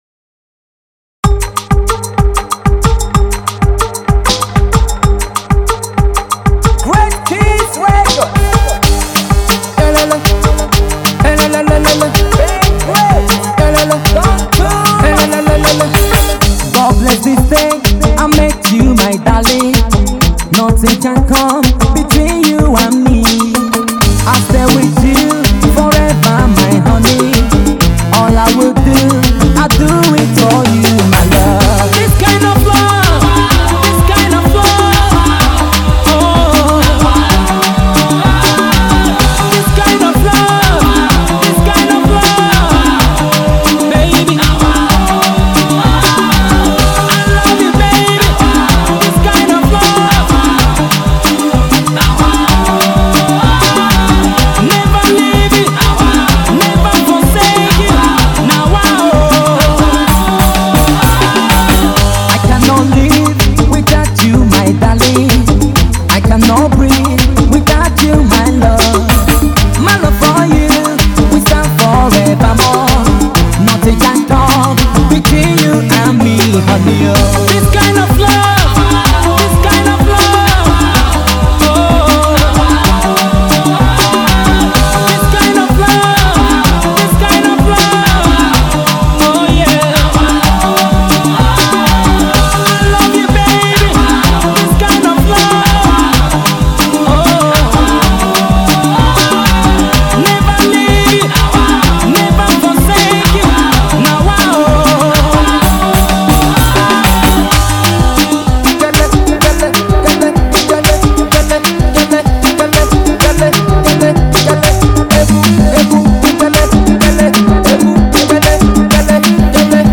Its a Nice Love song